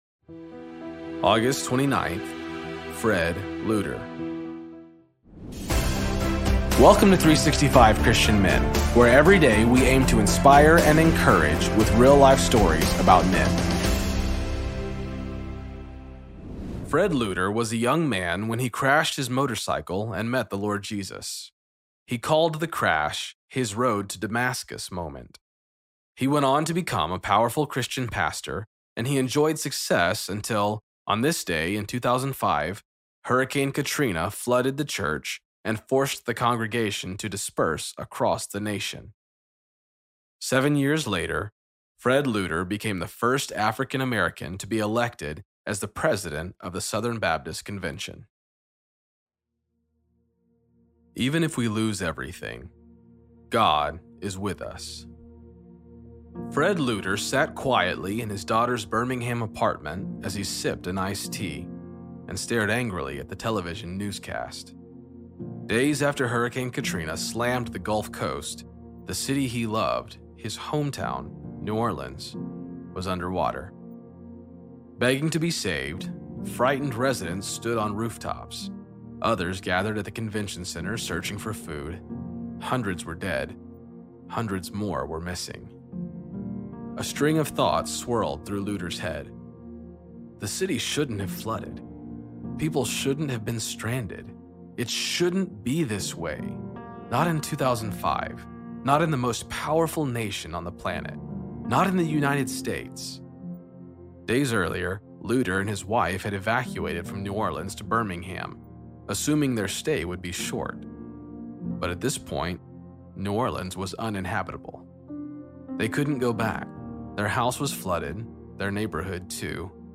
Story read by:
Introduction read by: